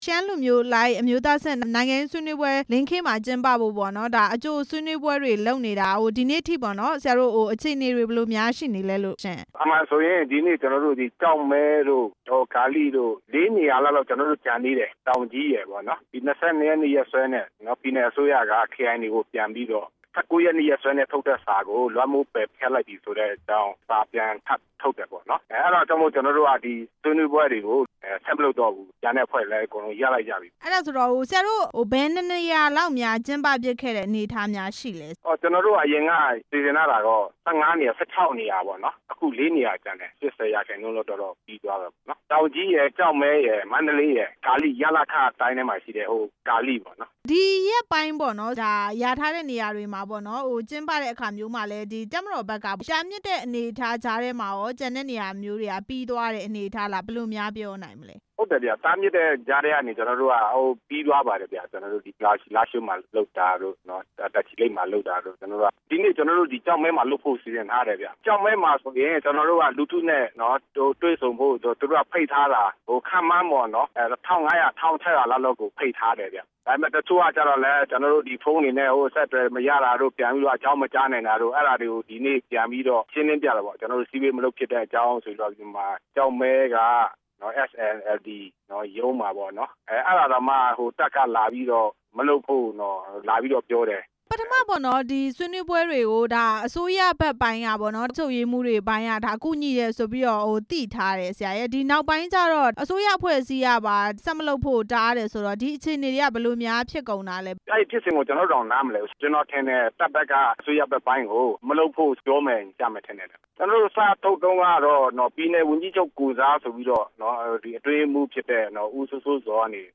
ရှမ်းလူထုတွေ့ဆုံပွဲတွေ ဆက်မကျင်းပဖို့ဆုံဖြတ်တဲ့ အကြောင်း မေးမြန်းချက်